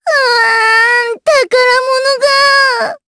Miruru-Vox_Dead_jp.wav